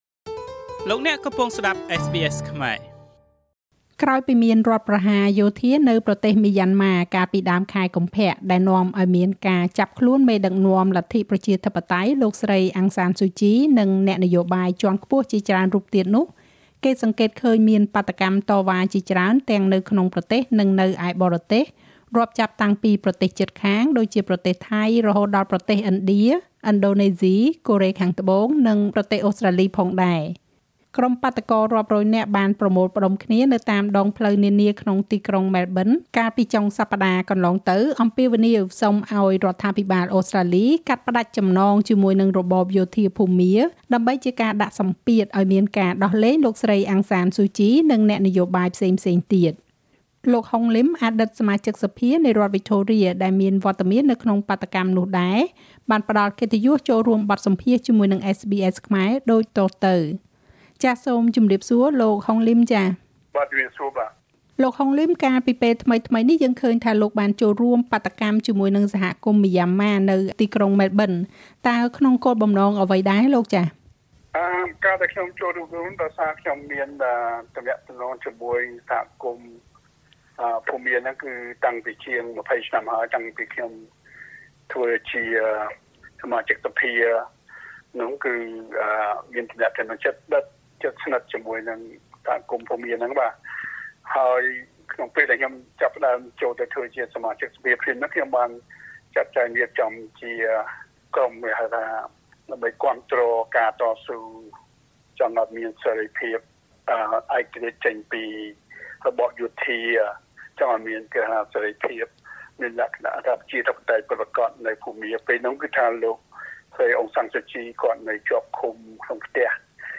ក្រុមអ្នកបាតុកររាប់រយនាក់បានប្រមូលផ្តុំគ្នានៅតាមដងផ្លូវនានាក្នុងទីក្រុងម៉ែលប៊ន អំពាវនាវសុំឲ្យរដ្ឋាភិបាលអូស្រ្តាលីកាត់ផ្តាច់ចំណងជាមួយនឹងរបបយោធាភូមា ដើម្បីជាការដាក់សម្ពាធឲ្យមានការដោះលែងលោកស្រី អាំង សានស៊ូជី និងអ្នកនយោបាយផ្សេងទៀត។ លោក ហុង លីម អតីតសមាជិកសភារដ្ឋវិចថូរៀដែលមានវត្តមាននៅក្នុងបាតុកម្មនោះ ផ្តល់កិត្តិយសចូលរួមបទសម្ភាសន៍ជាមួយ SBS ខ្មែរដូចតទៅ។